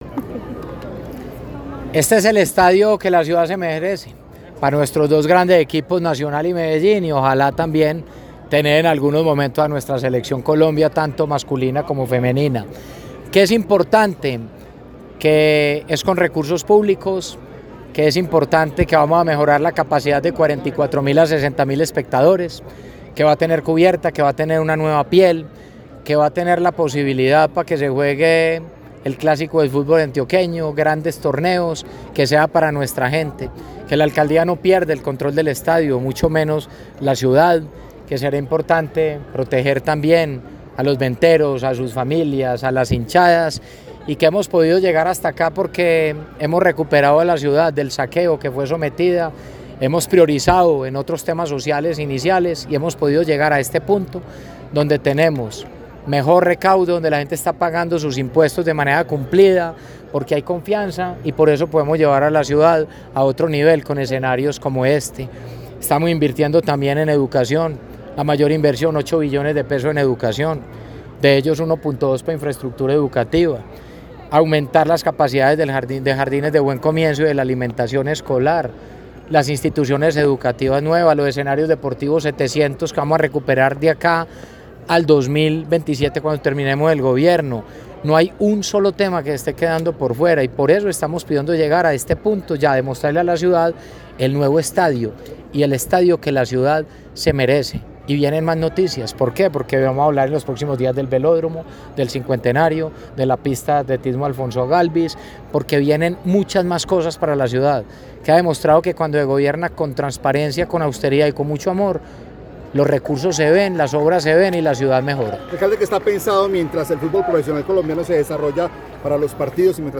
Declaraciones-del-alcalde-de-Medellin-Federico-Gutierrez.mp3